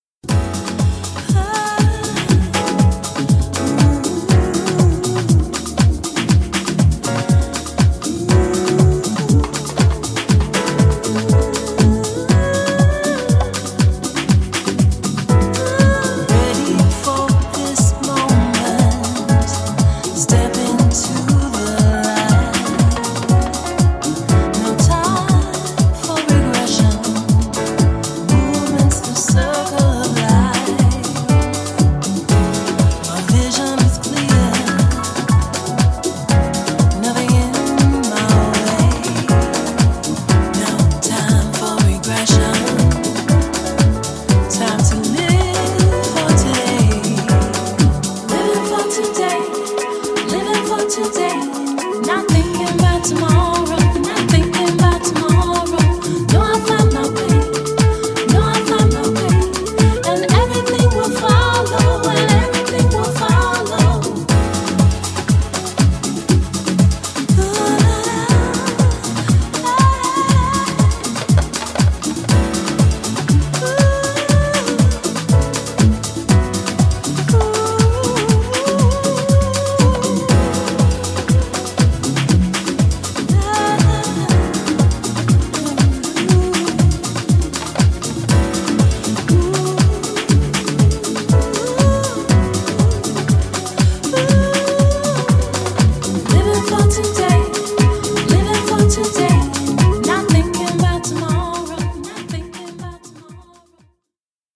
パーカッションやピアノ等がフィーチャーされた躍動感のあるトラックにソウルフルで丁寧に歌い上げるヴォーカルが◎！